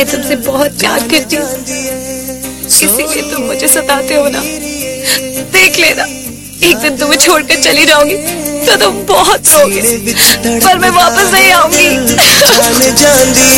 Category: Sad Ringtones